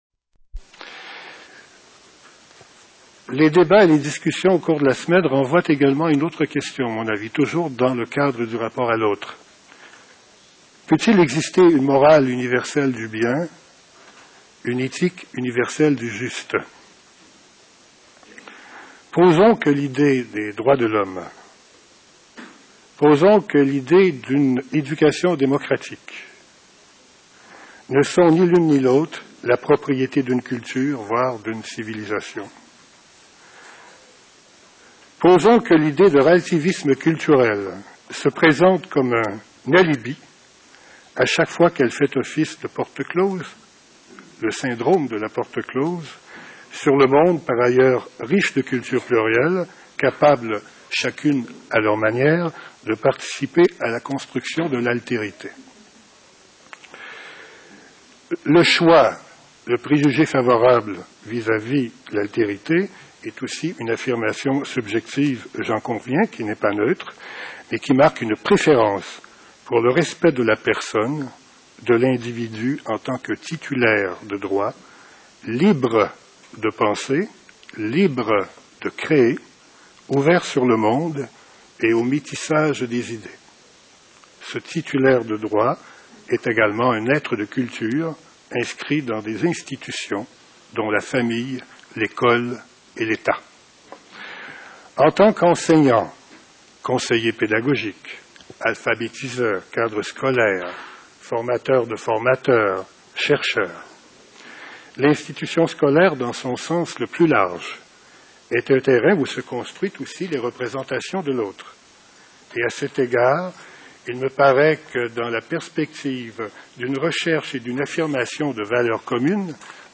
19e session internationale du CIFEDHOP
Genève, du du 8 au 14 juillet 2001